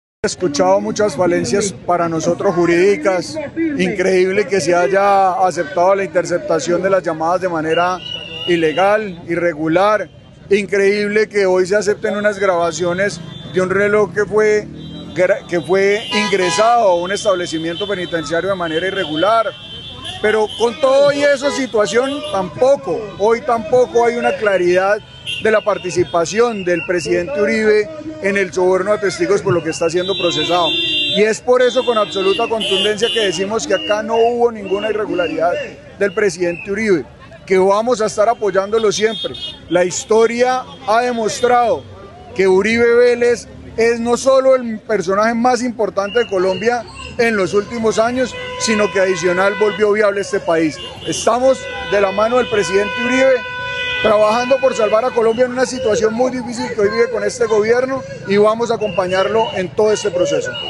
Oscar Villamizar, representante a la cámara Centro Democrático
Habló el representante a la cámara Óscar Villamizar: